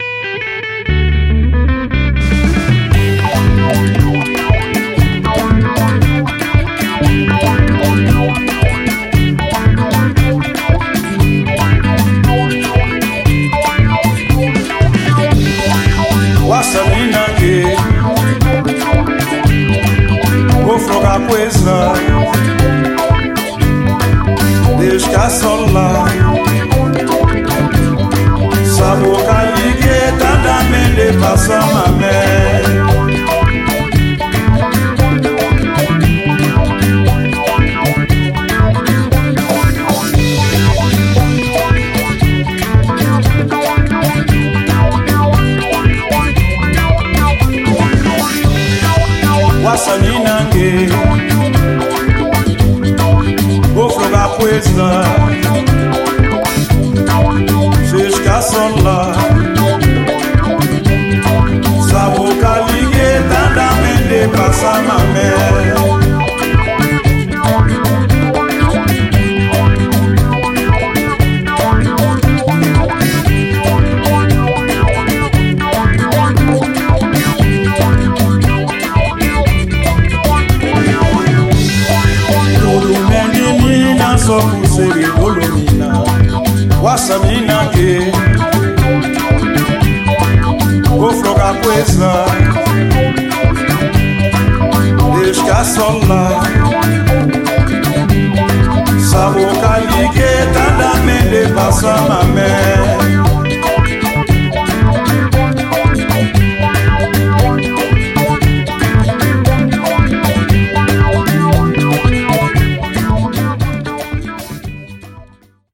Soul Funk Jazz